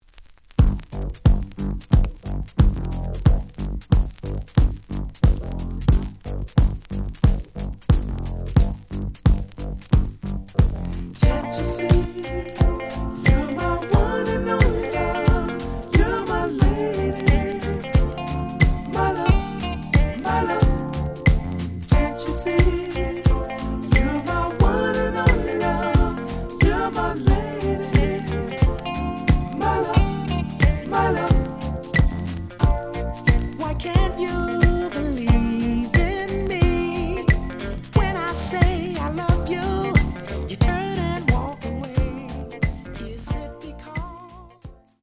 The audio sample is recorded from the actual item.
●Genre: Soul, 80's / 90's Soul
Slight noise on both sides.